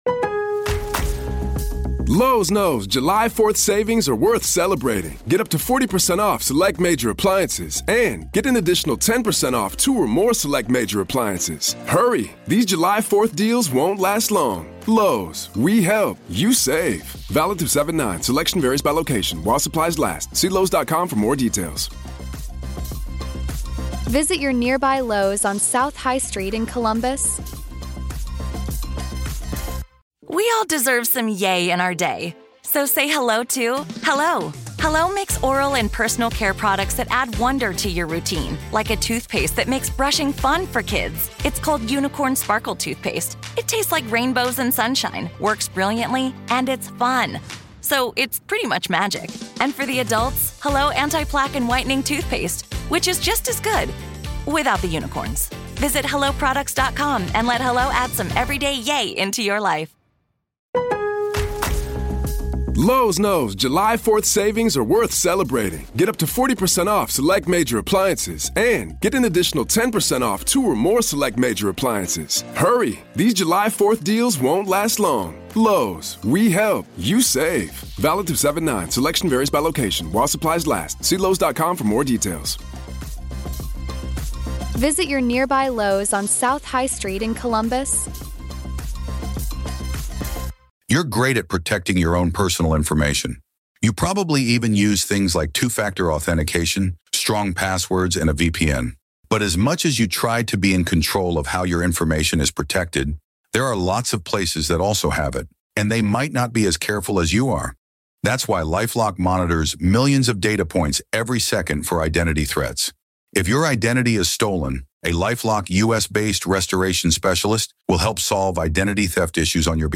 In this episode, we dive deep into Missouri’s haunted history and explore the legends that continue to intrigue and terrify. This is Part Two of our conversation.